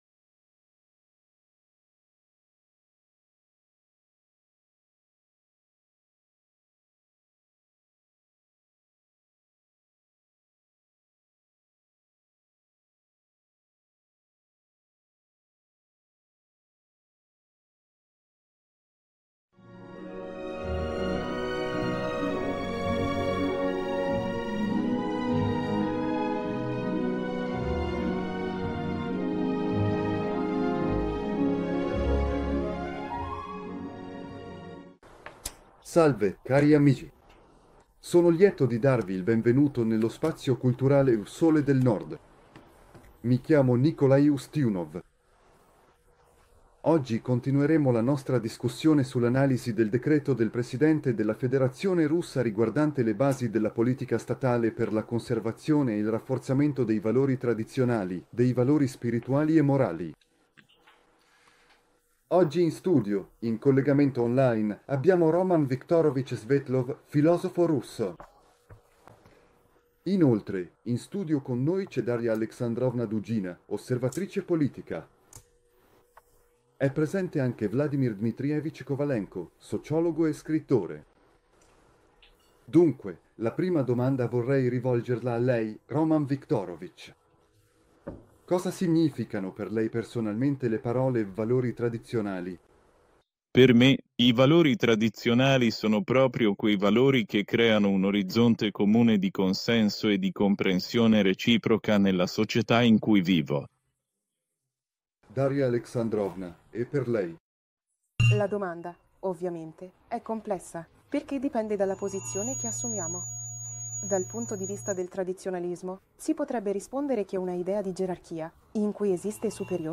Prima parte di un incontro di straordinario rilievo filosofico e politico dedicato all’analisi del Decreto del Presidente della Federazione Russa per la conservazione e il rafforzamento dei valori tradizionali.